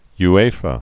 (y-āfə, -, wēfə)